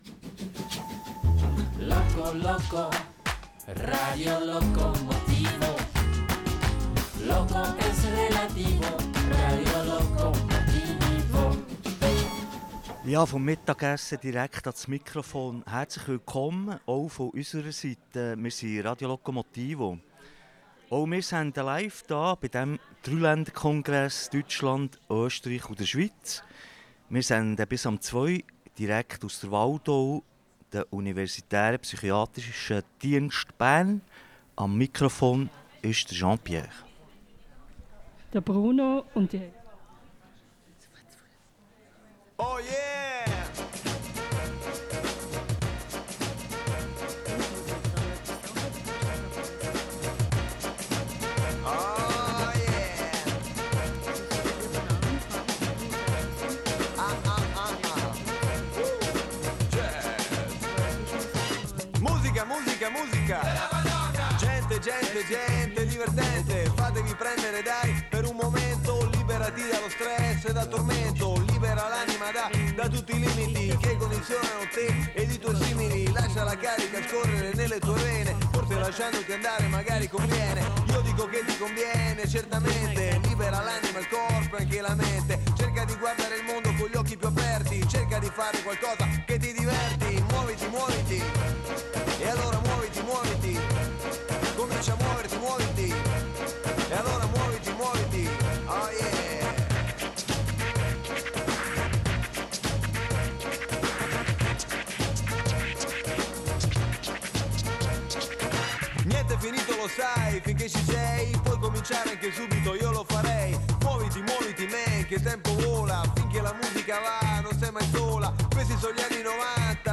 Verrücktes Radio mit Seele: Betroffene, Angehörige und Berufsleute aus der Psychiatrie machen gemeinsam Radio rund ums Thema Psychiatrie.